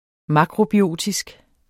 Udtale [ ˈmɑkʁobiˌoˀtisg ]